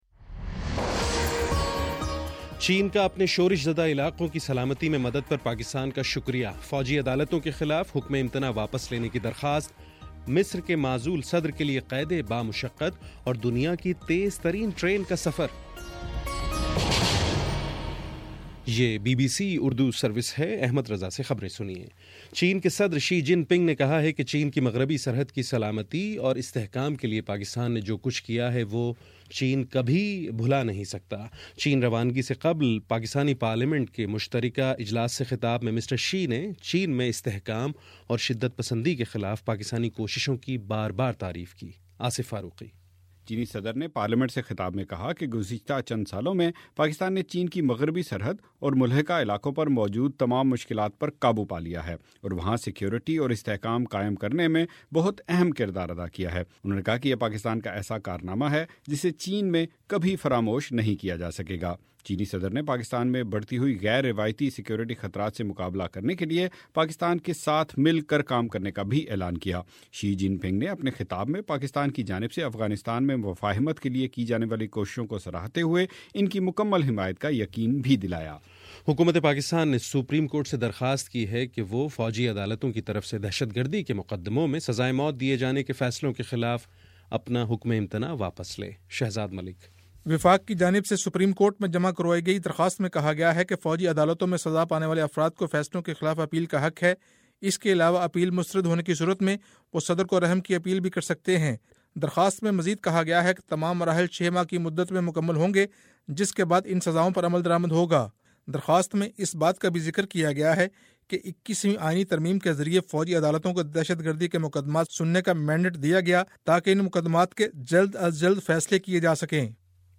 اپریل21: شام سات بجے کا نیوز بُلیٹن